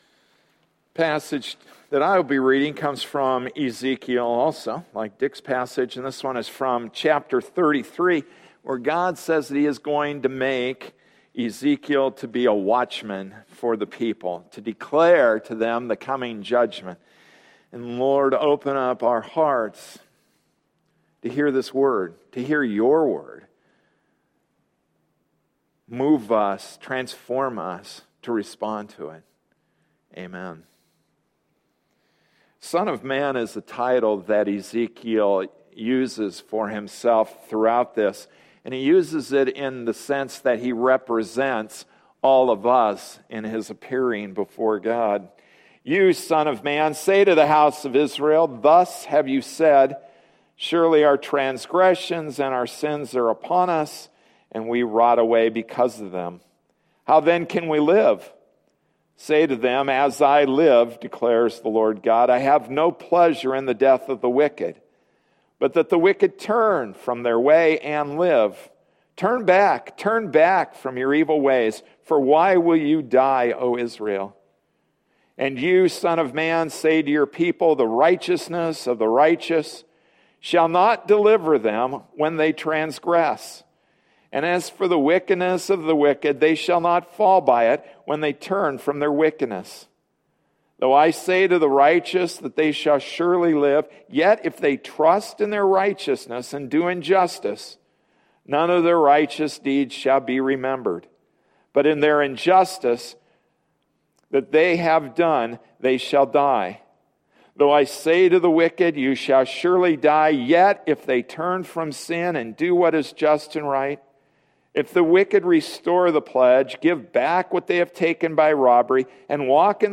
November 10, 2013 Restoration Passage: Ezekiel 33:10-20 Service Type: Sunday Morning Service Restoration Ezekiel Ch8 The spirit takes Ezekiel on an inspection tour of the Temple.